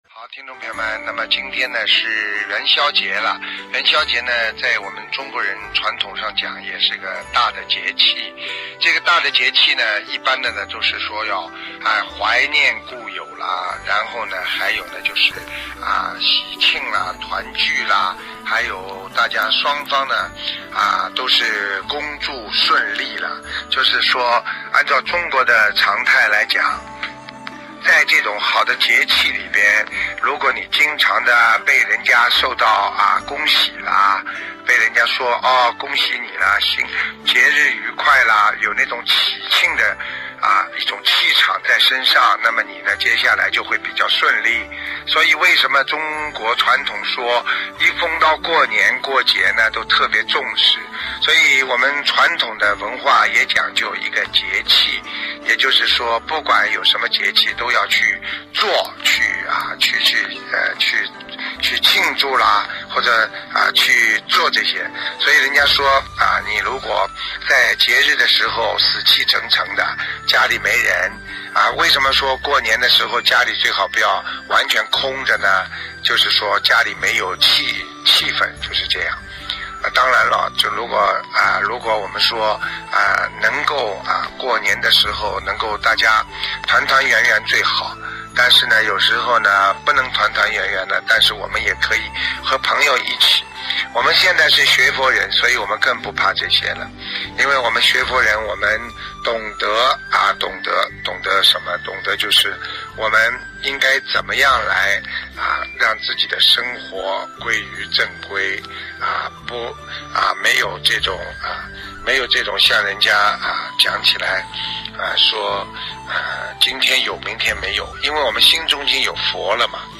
音频：意大利米兰观音堂！2023年共度元宵佳节！